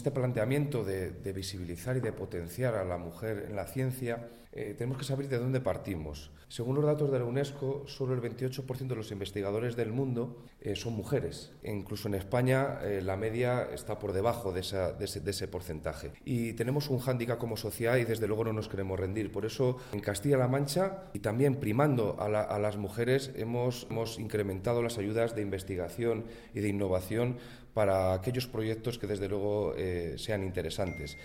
El delegado de la Junta en Guadalajara habla de la necesidad de despertar vocaciones científicas desde la infancia, especialmente en las niñas.